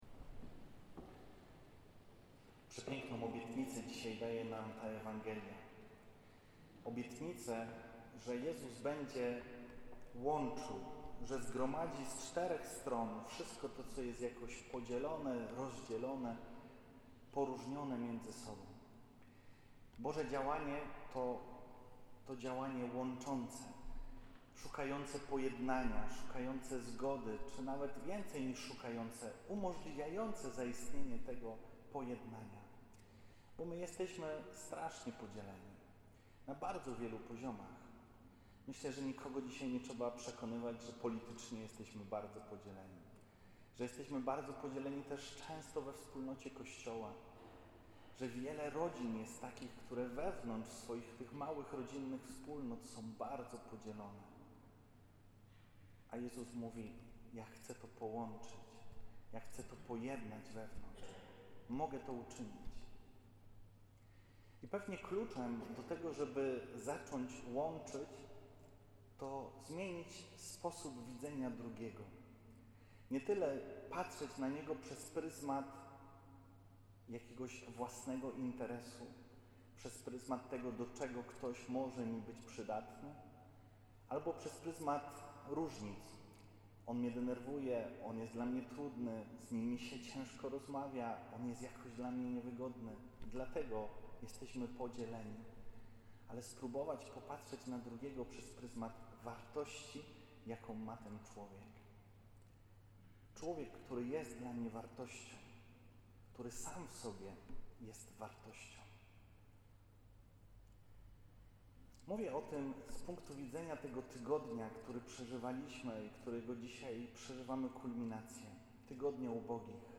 Homilia z XXXIII Niedzieli Zwykłej - "Spotkanie ubogaca" (2024-11-17)